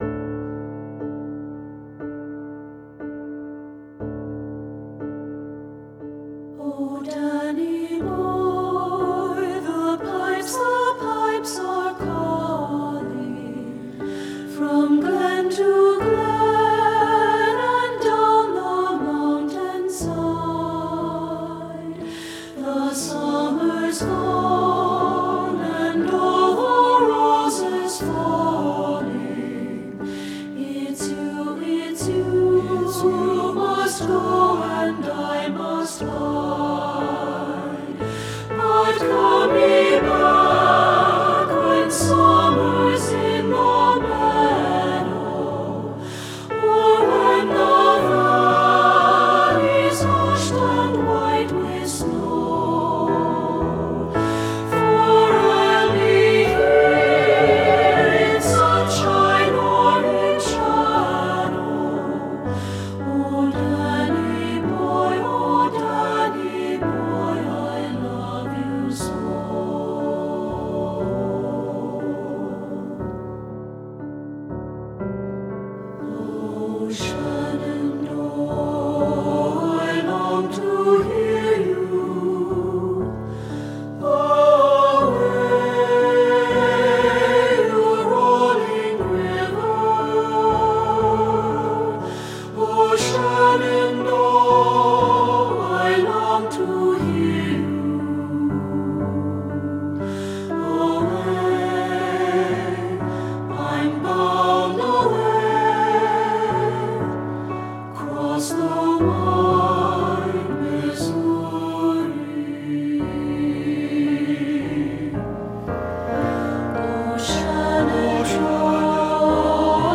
SAB Voices with Piano
• Soprano
• Alto
• Bass
• Piano
Studio Recording
Ensemble: Three-part Mixed Chorus
Key: D major, E major
Tempo: Reflective (q = 60)
Accompanied: Accompanied Chorus